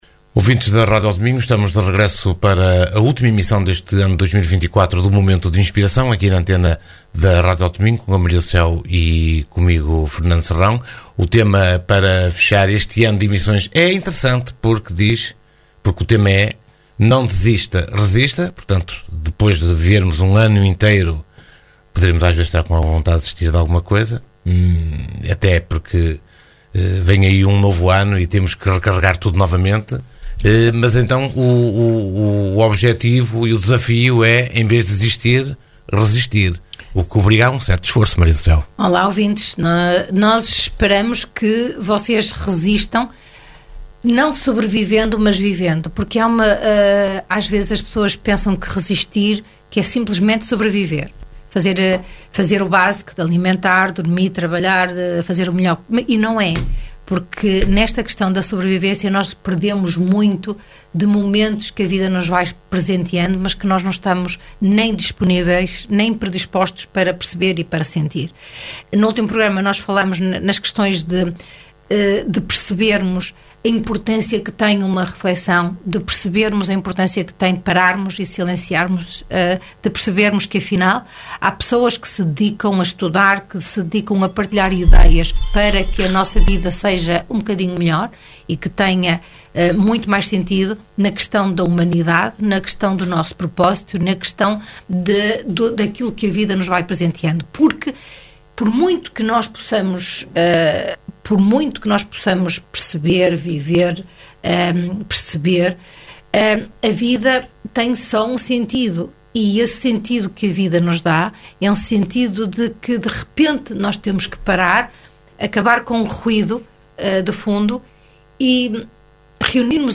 Momento de Inspiração (programa) | Segundas 22h.